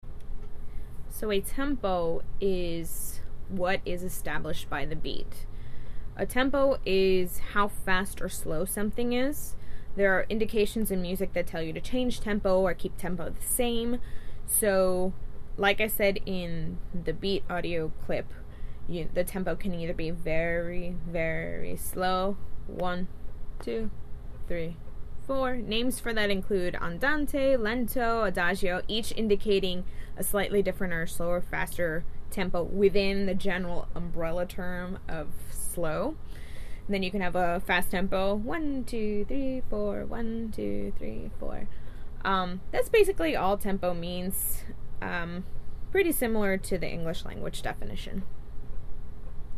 Tempo – This is how fast or slow something is.
mekdost-tempo.mp3